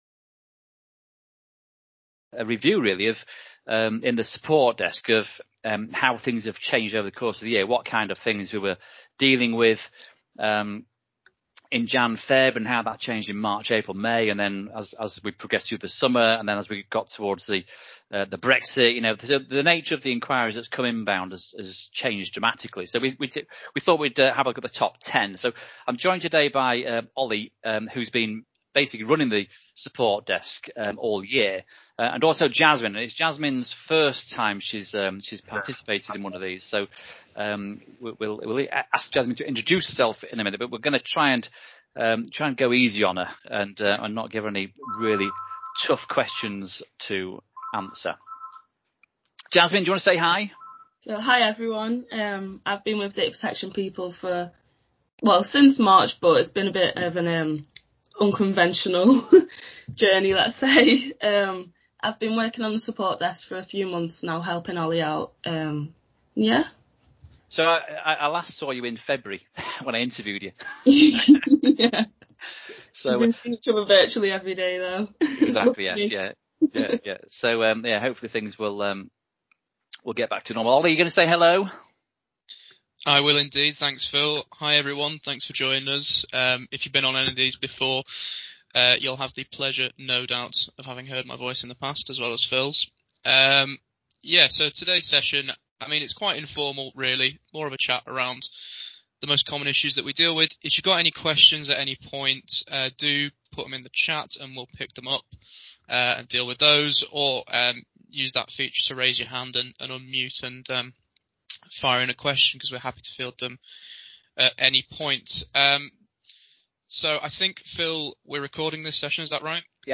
A discussion with Data Protection experts speaking about the biggest challenges of 2020